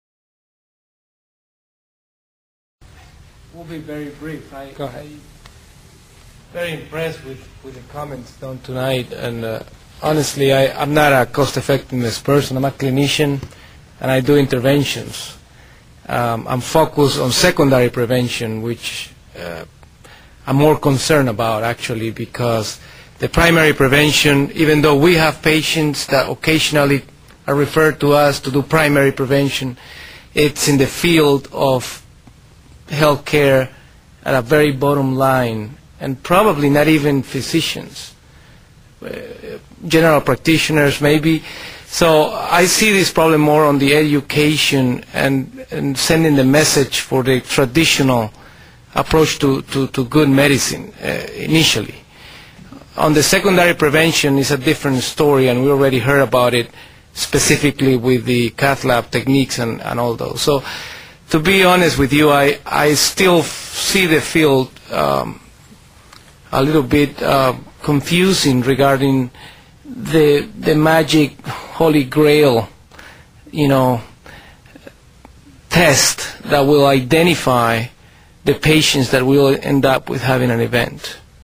Annual Scientific Conference of the American College of Cardiology 2004